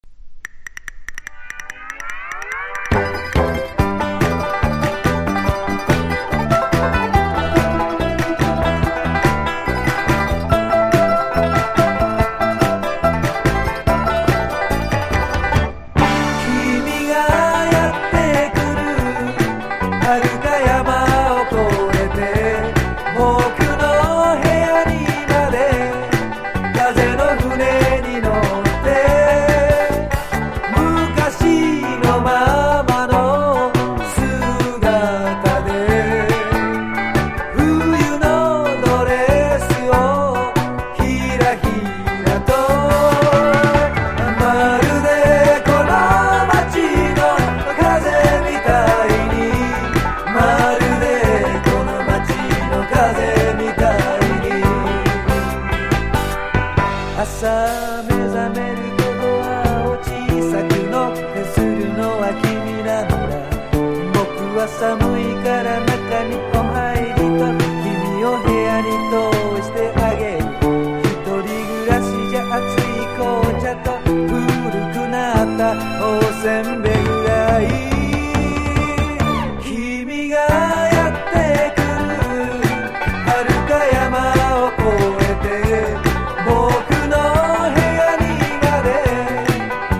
SSW / FOLK